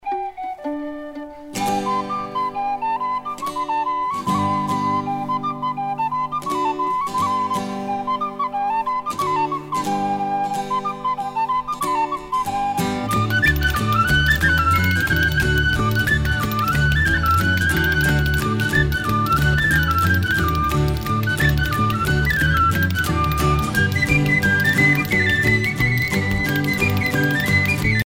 danse : fisel (bretagne)
Pièce musicale éditée